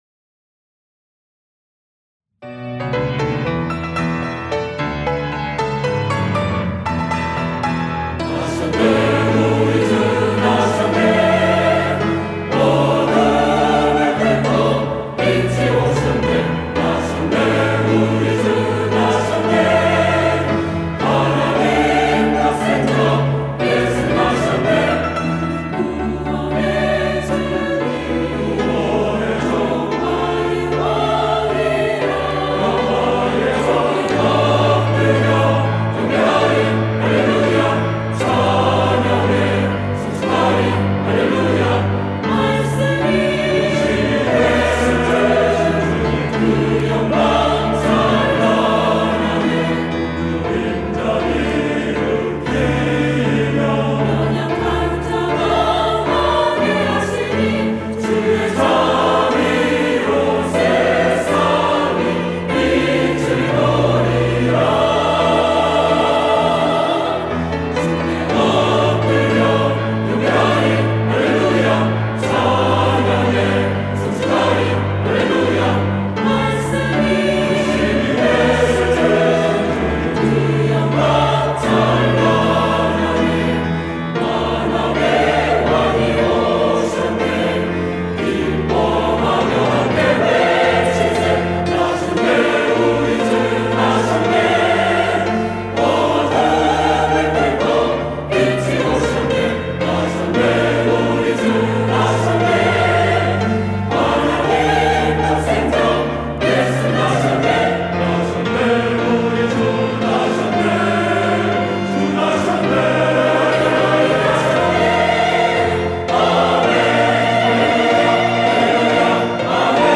할렐루야(주일2부) - 왕의 왕이 나셨네
찬양대